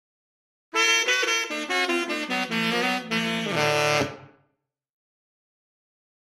Saxophone
Sax Melody - Diphthong